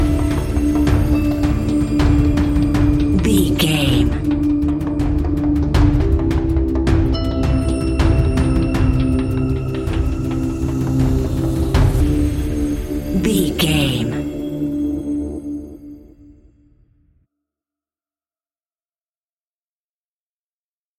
In-crescendo
Thriller
Aeolian/Minor
scary
ominous
dark
suspense
eerie
ticking
electronic music
Horror Pads
Horror Synths